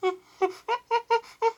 maj : you can find 2 samples of chimpanzee... very impatient to hear your creations.
Chimpanzee.wav